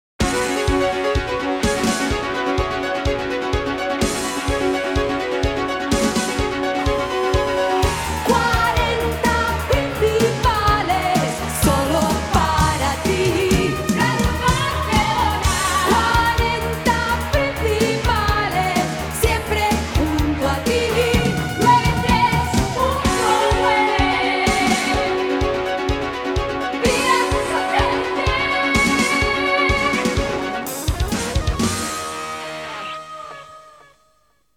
Indicatiu "heavy" de l'emissora i del programa.